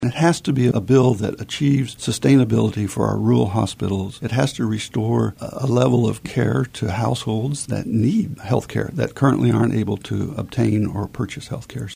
Tom Phillips, a Kansas House of Representative member representing the 67th district, appeared on today’s episode of In Focus to preview some of what he will be working on in the upcoming legislative session in the areas of healthcare, higher education and “dark store theory.”